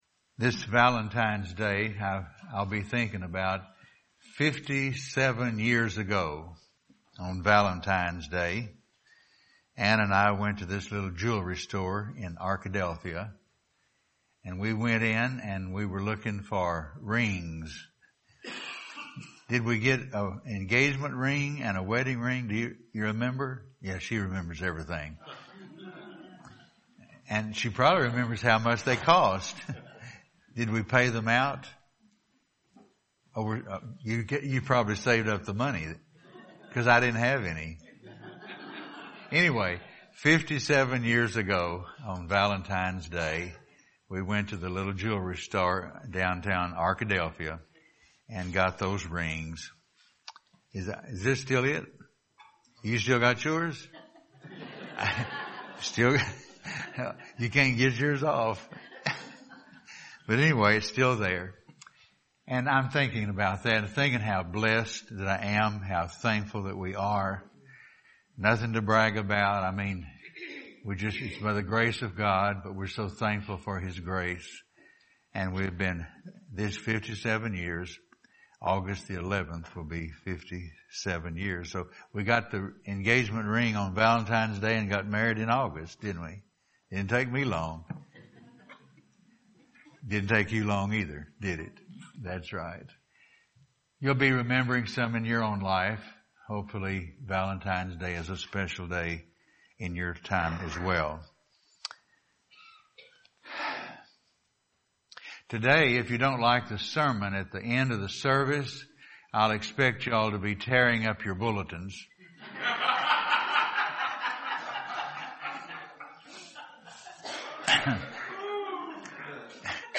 Passage: 1 Corinthians 13:1-8 Service Type: Sunday Morning